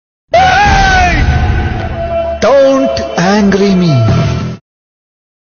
Don't angry me comedy sound
Download Comedy meme Don’t angry me comedy sound
Dont-angry-me-comedy-sound.mp3